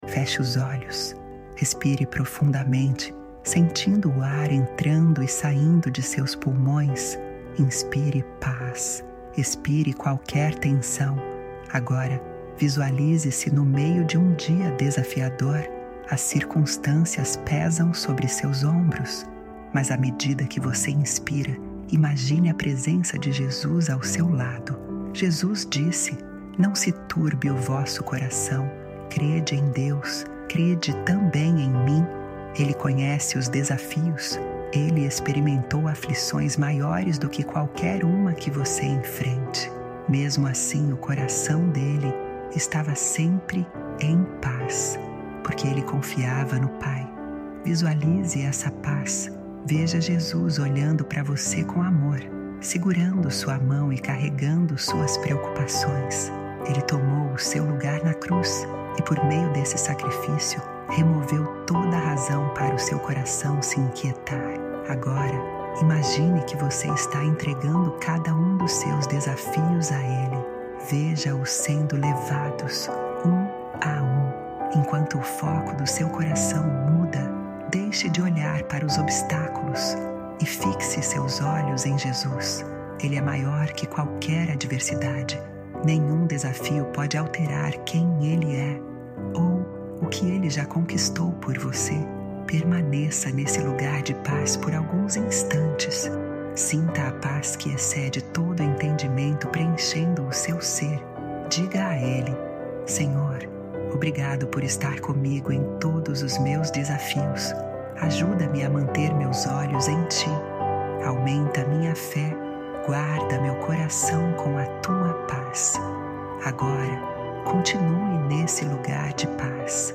Momento Poderoso de um Encontro ao Vivo em que muitas vidas foram curadas e começaram uma nova versão de si mesmas livres de crenças limitantes.